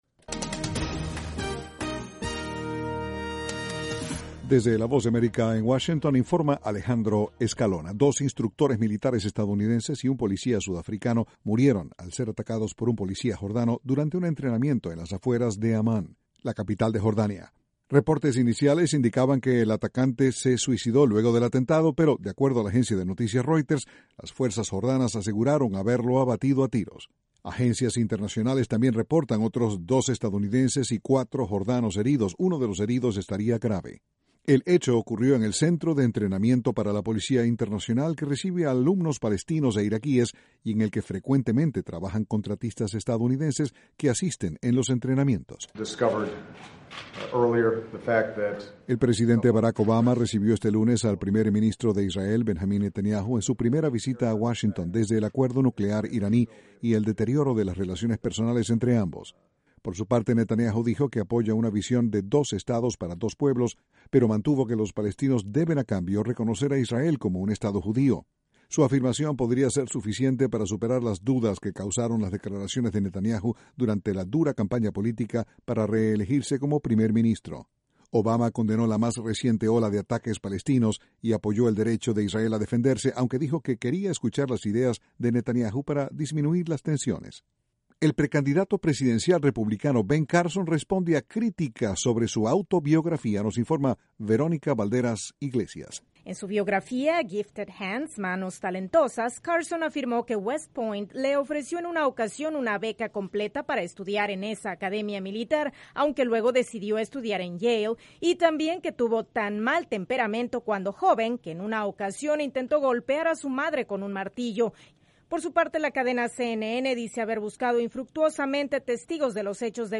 VOA: Noticias de la Voz de América, Washington